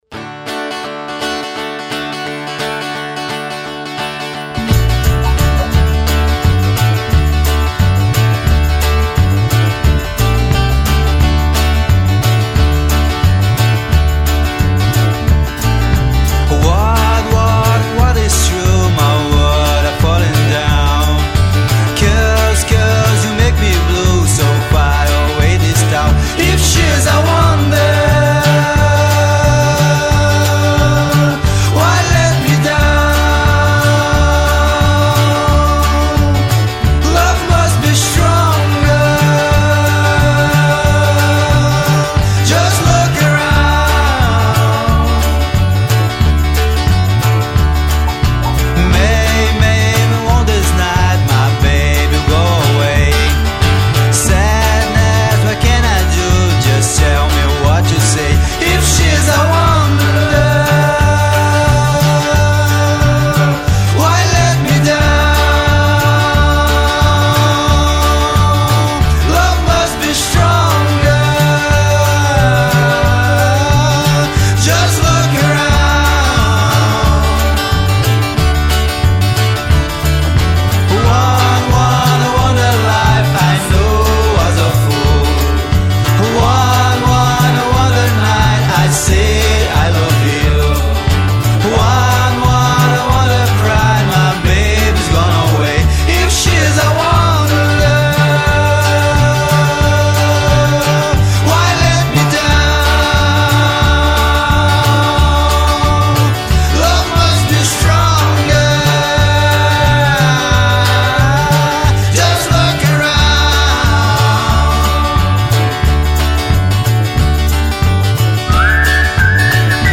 1714   03:22:00   Faixa:     Rock Nacional